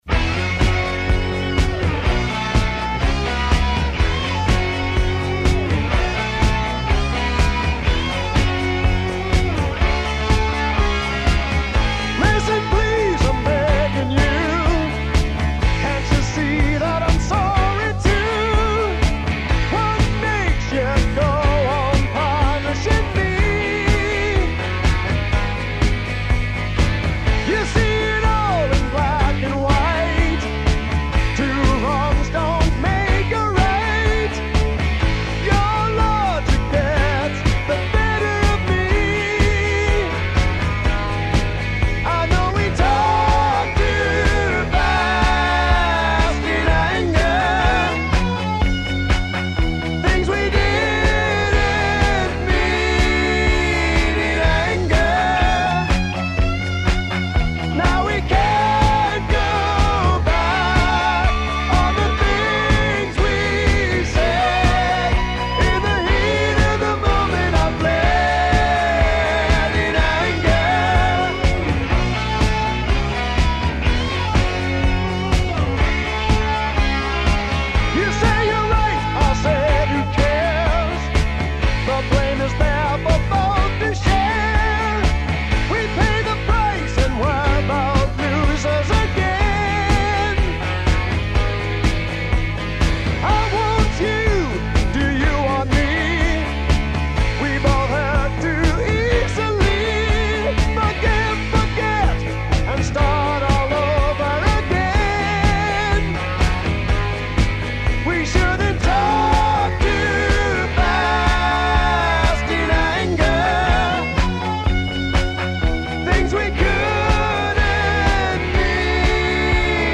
The pathos of some Orbison stories mixed with rage.